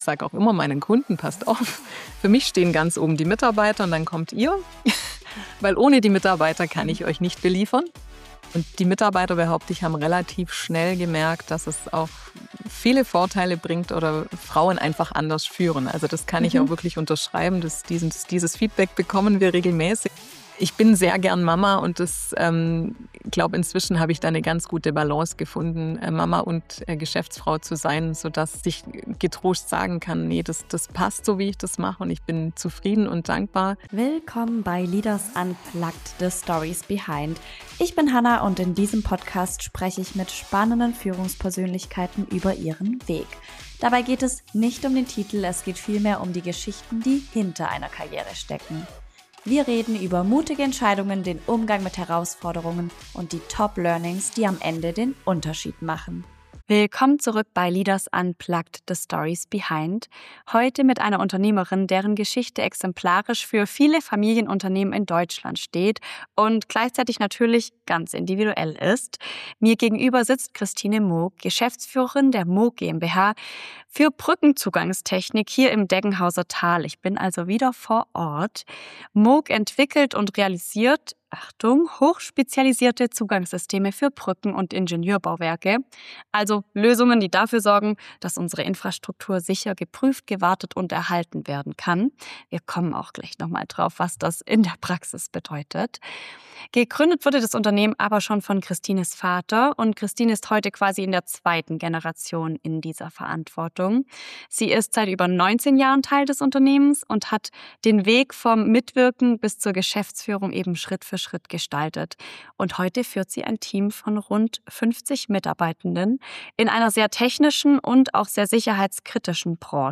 Ein ehrliches Gespräch über Mut, Bauchentscheidungen, Mitarbeiterbindung und warum Erfolg nicht immer Wachstum bedeuten muss.